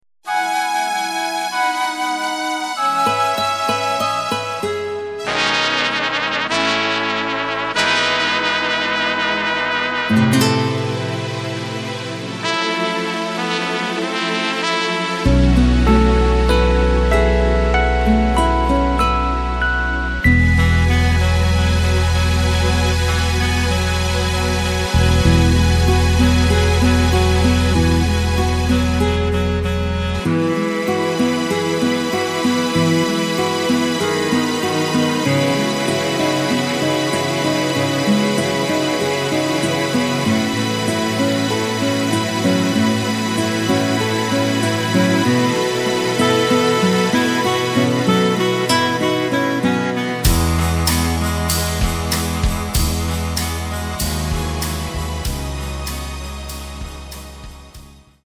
Takt:          4/4
Tempo:         96.00
Tonart:            E
Playback mp3 Demo